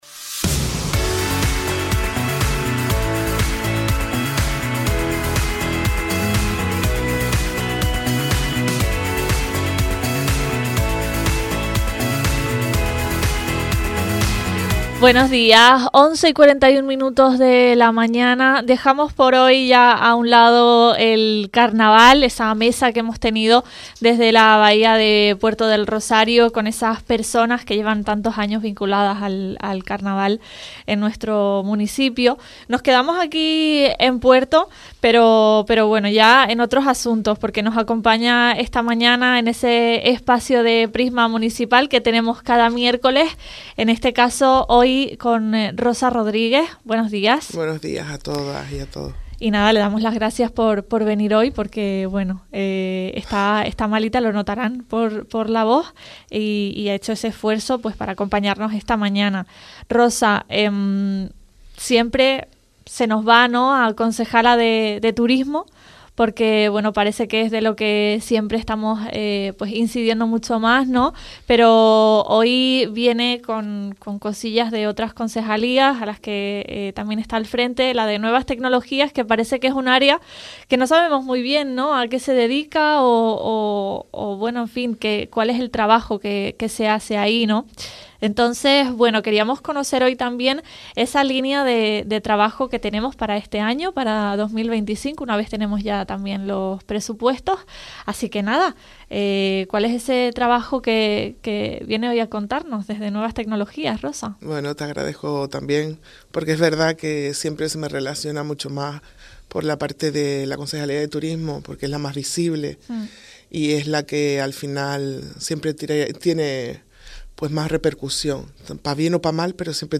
Contamos esta mañana con Rosa Rodríguez, concejala del Ayuntamiento de Puerto del Rosario, quien nos ha trasladado la línea de trabajo de su departamento para 2025, además, ha dado a conocer las las iniciativas que se desarrollan desde la Concejalía de Nuevas Tecnologías.